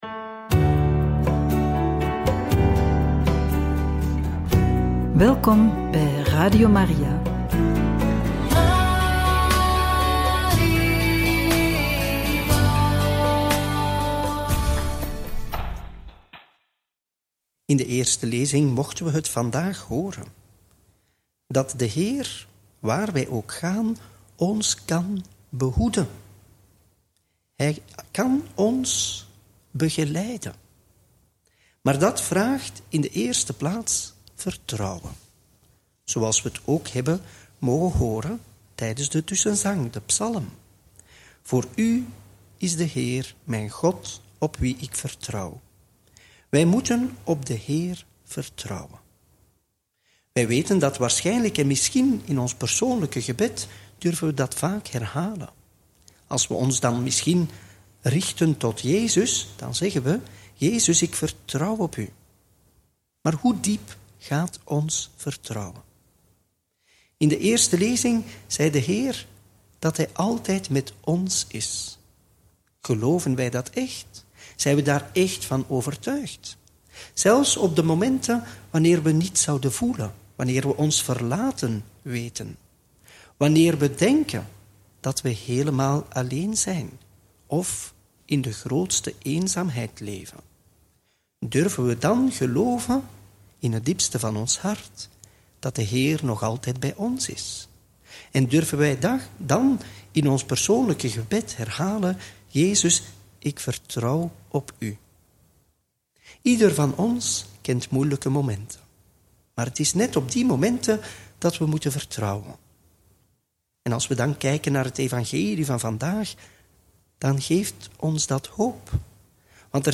Homilie bij het Evangelie van maandag 7 juli 2025 – Mt 9, 18-26